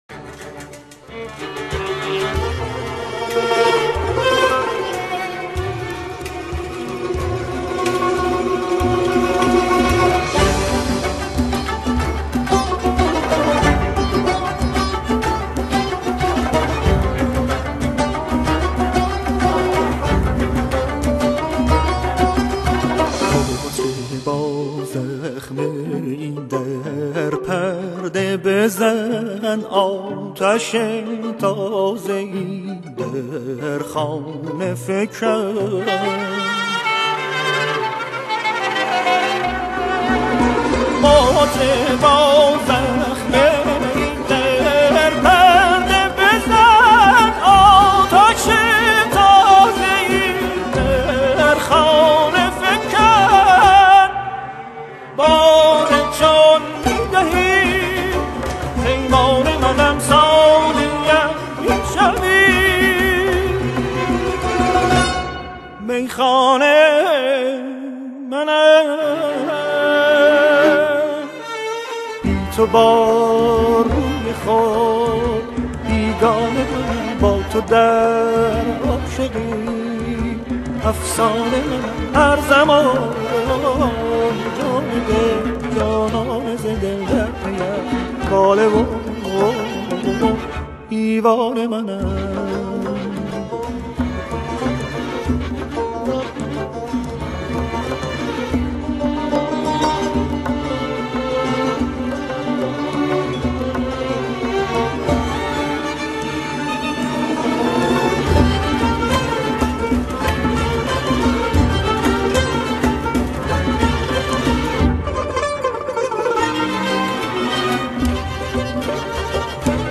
کمانچه‌ها و تار
تصنیف